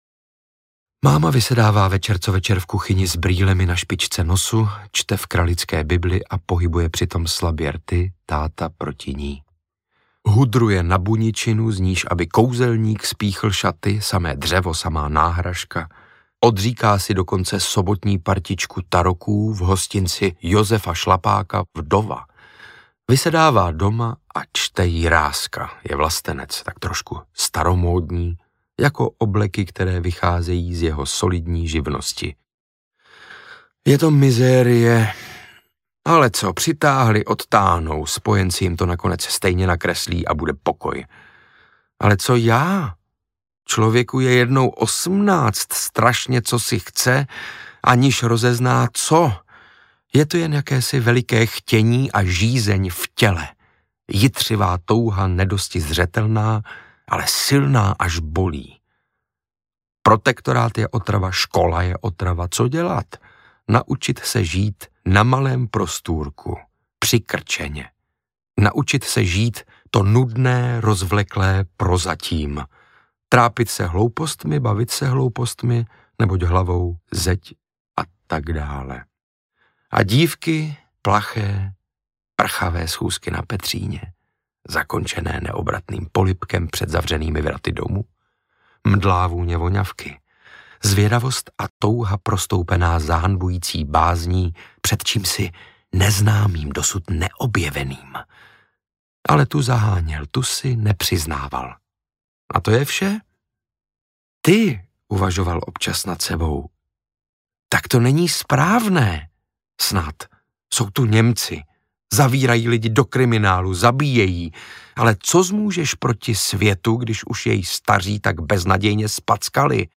Romeo, Julie a tma audiokniha
Ukázka z knihy
• InterpretSaša Rašilov